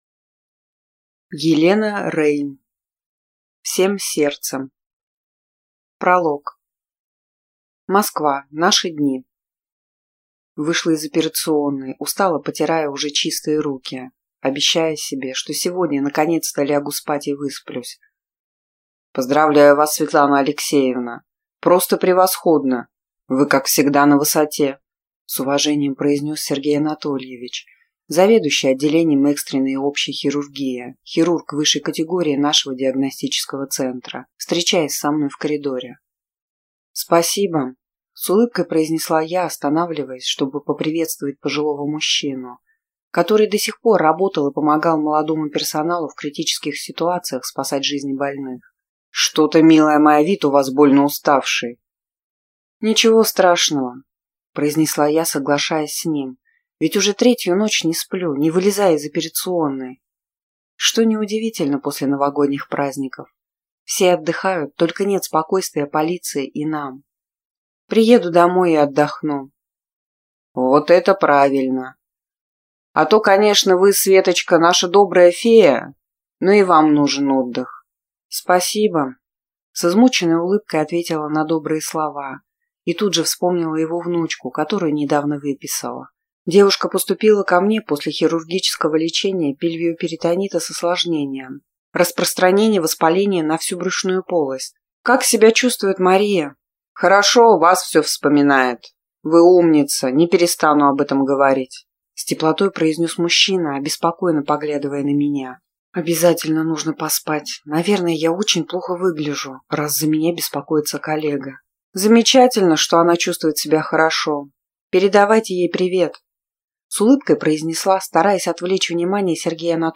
Аудиокнига Всем сердцем | Библиотека аудиокниг